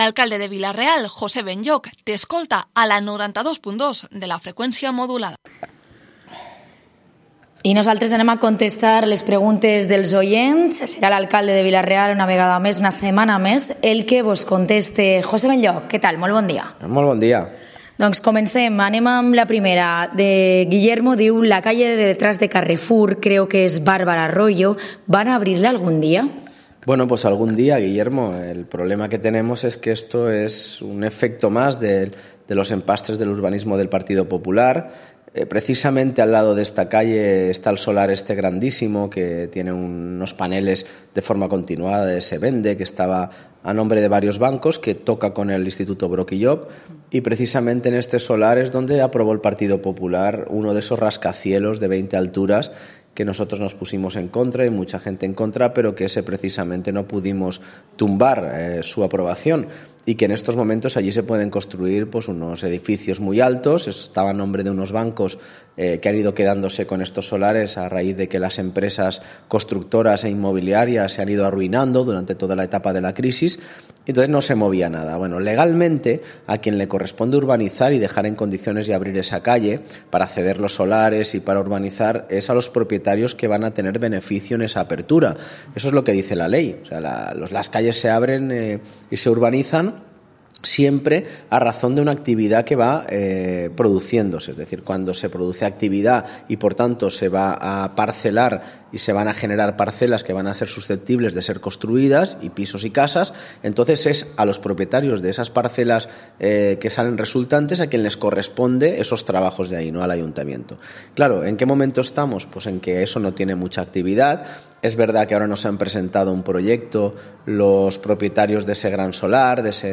Espacio semanal dedicado al ciudadano, que podrá preguntar y exponer sus inquietudes al Alcalde de Vila-real, José Benlloch. Cada semana responderá en directo a cuestiones planteadas por nuestros oyentes.